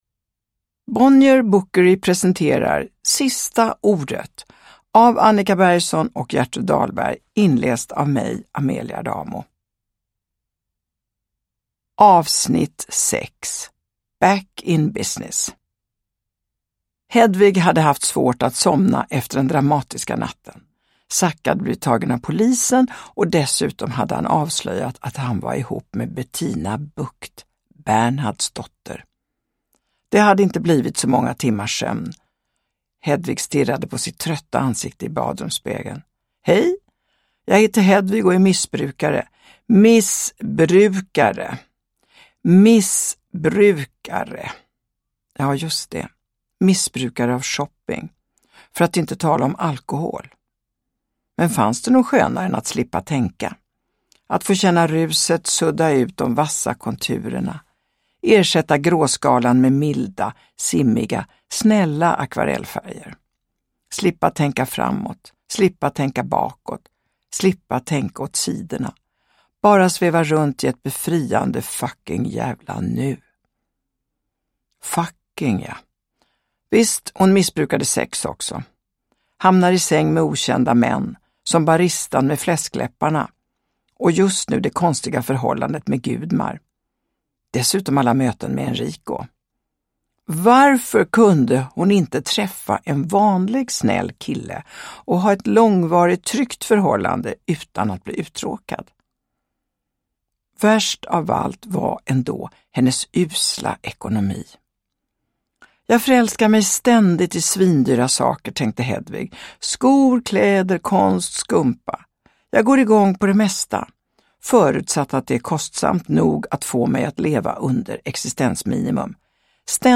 Sista ordet. S1E6, Back in business – Ljudbok – Laddas ner
Uppläsare: Amelia Adamo